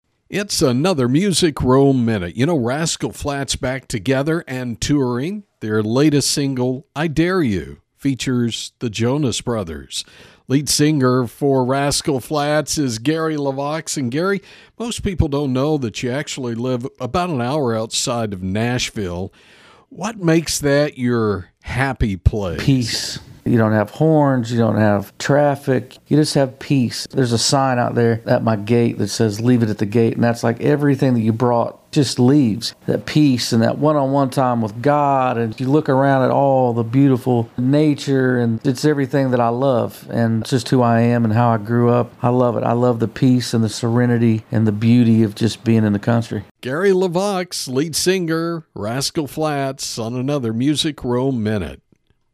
Music Row Minute is a daily radio feature on 106.1FM KFLP
Gary told us about his little piece of heaven on earth and what he gets from being there.